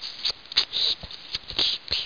1 channel
00953_Sound_toothpick.mp3